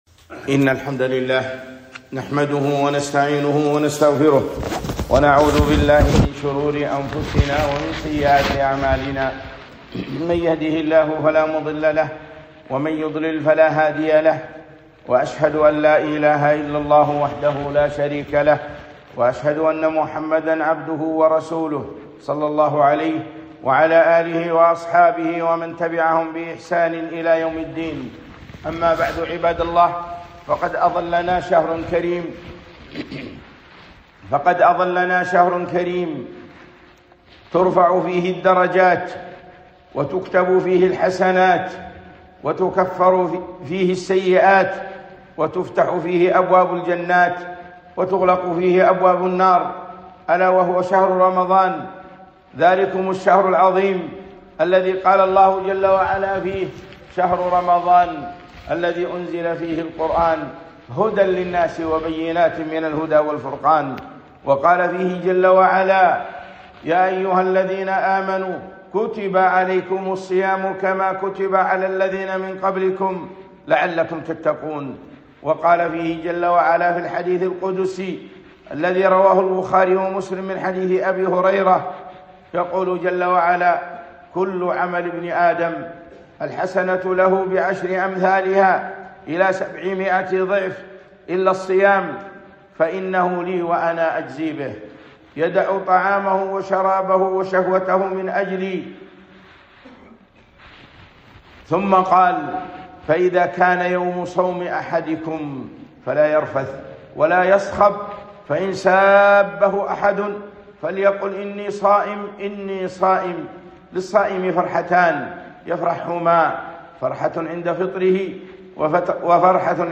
خطبة - فيما ينبغي أن يكون عليه المسلم في رمضان